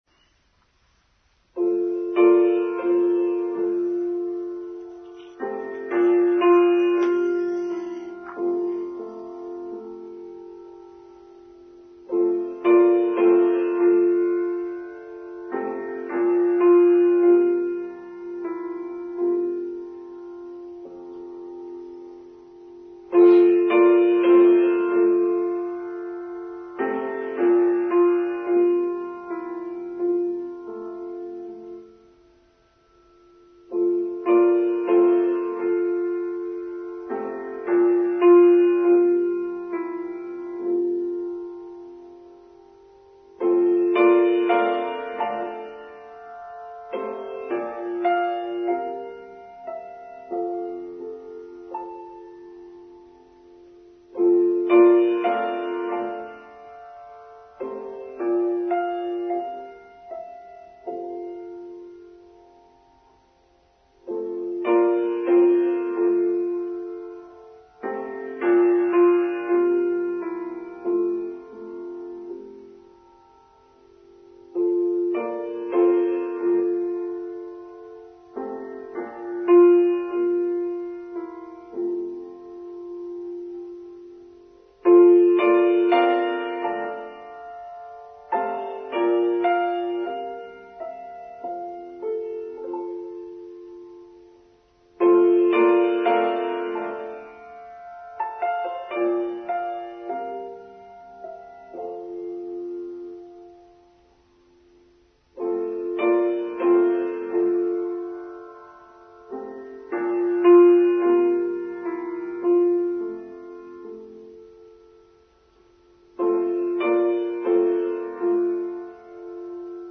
Detachment: Online Service for Sunday 12th March 2023